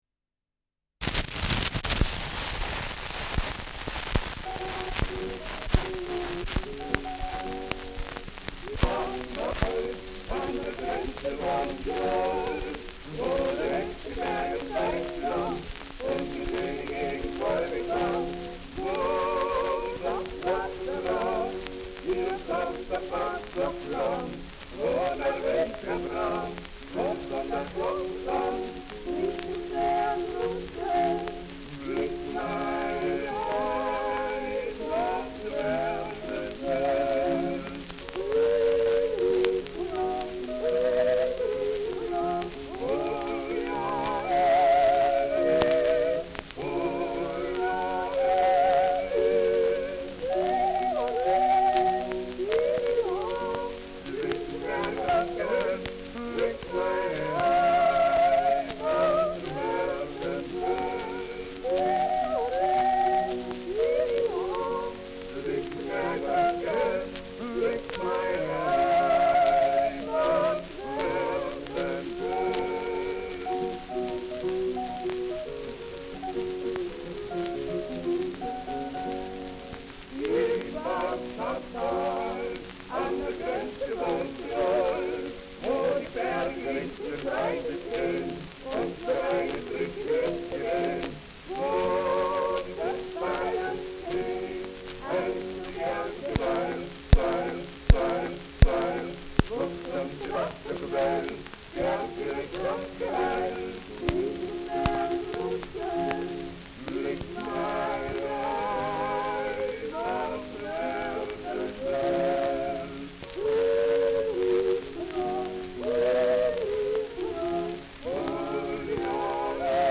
Schellackplatte
Starkes Grundrauschen : Gelegentlich starkes Knacken : Hängt im letzten Drittel : Verzerrt an lauteren Stellen
Zugspitzsänger (Interpretation)